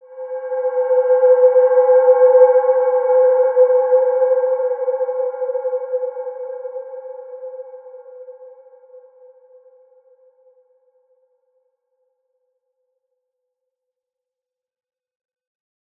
Wide-Dimension-C4-mf.wav